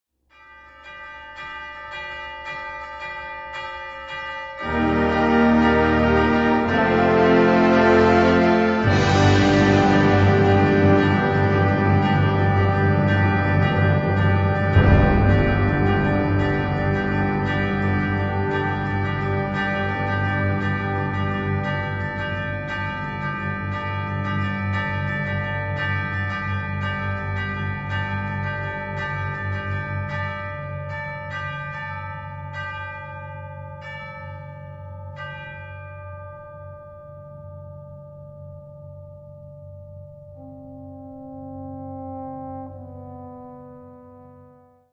Gattung: Zeitgenössische Originalmusik
Besetzung: Blasorchester